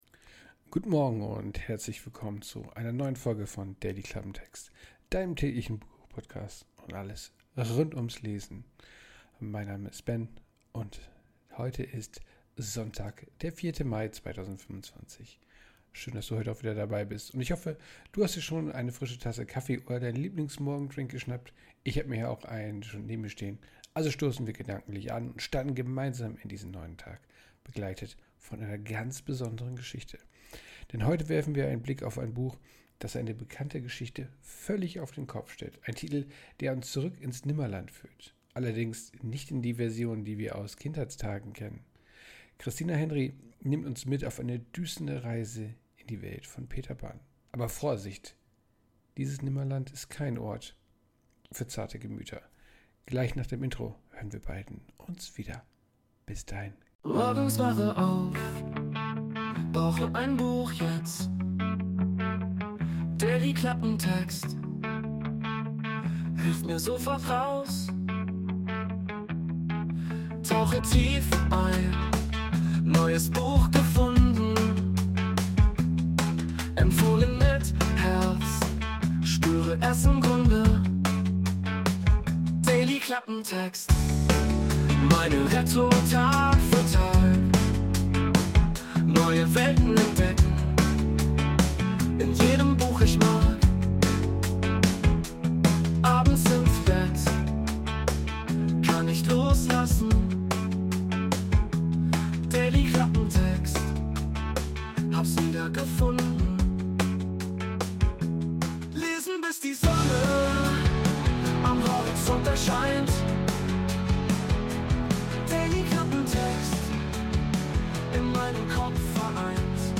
Intromusik: Wurde mit der KI Sonos erstellt.